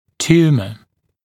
[‘t(j)uːmə][‘т(й)у:мэ]опухоль, новообразование, бластома, неоплазма